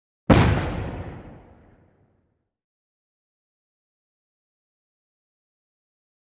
Grenade: Explosion; One Sharp Explosion With Short Echo. Close Up Perspective.